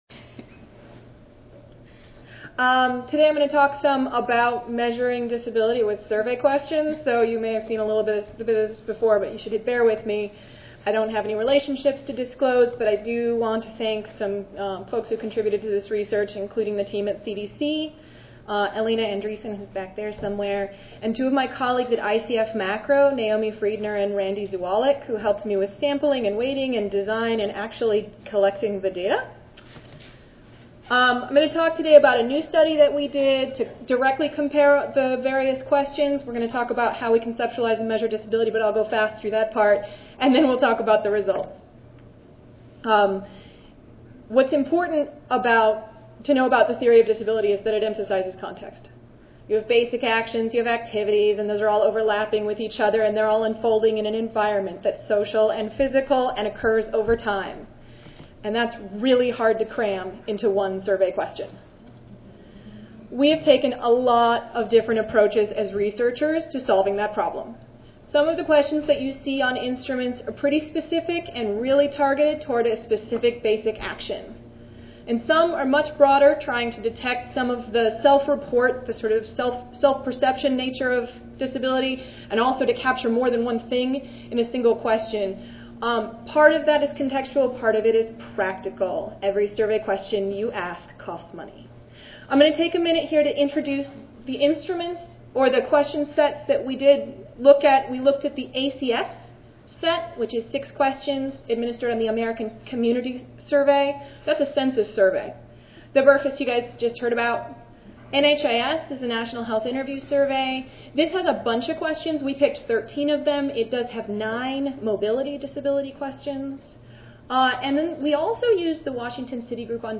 3129.0 Conceptualizing, Defining and Measuring Disability I Monday, October 31, 2011: 10:30 AM Oral Disability is a dynamic phenomenon which has no universally accepted definition.